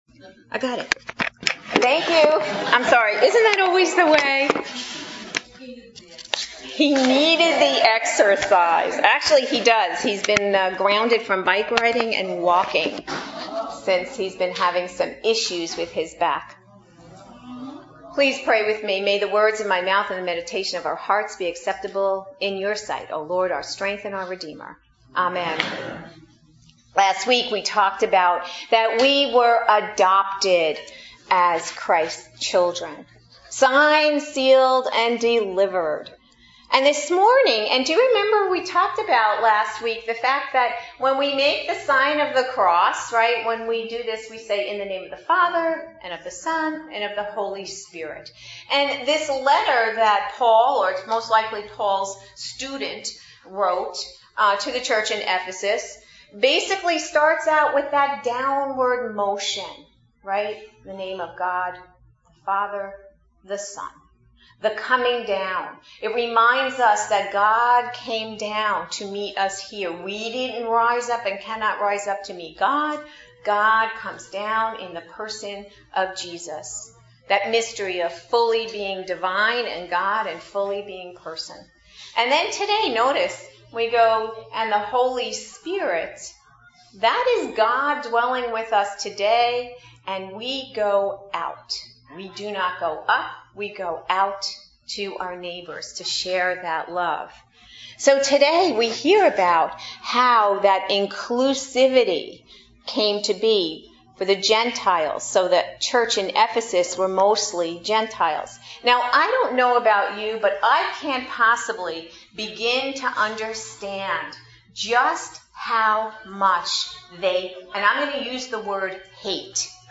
Adult Sermons